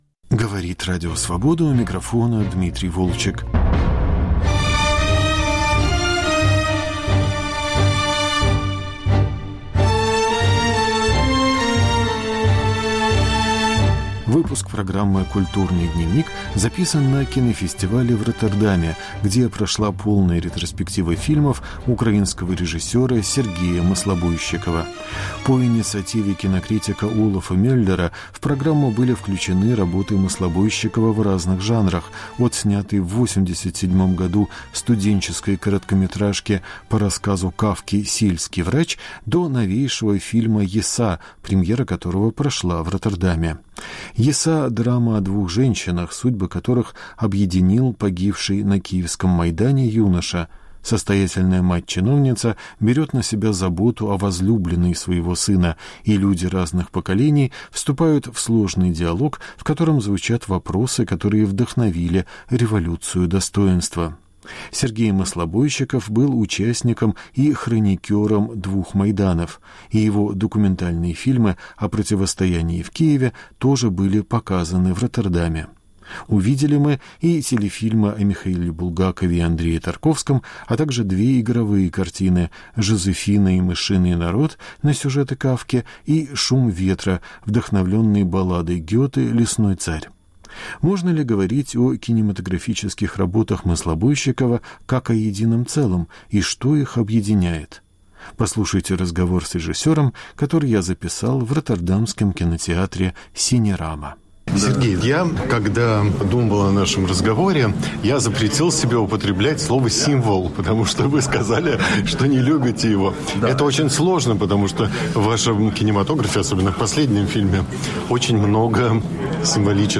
Разговор с украинским режиссером на кинофестивале в Роттердаме